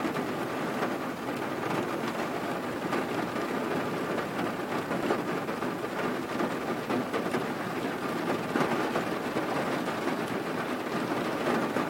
rain_roof_metal.ogg